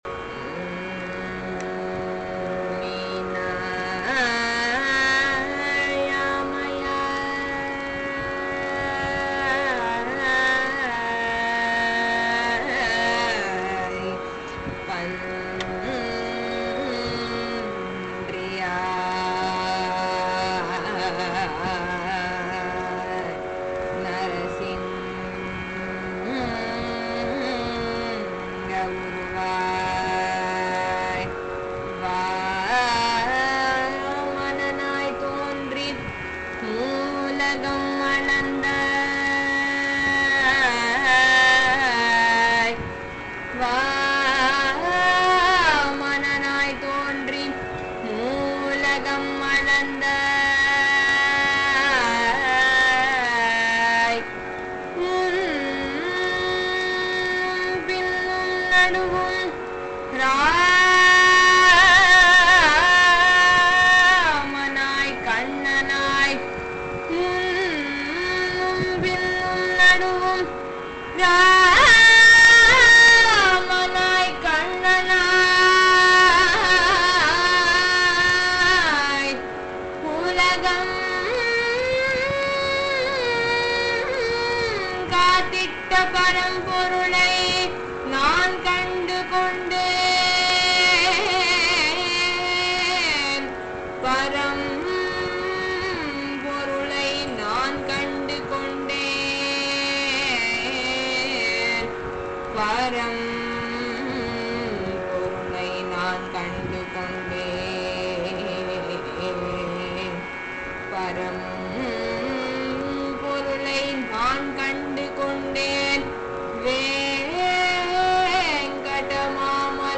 ராகம்: அமிர்தவர்ஷினி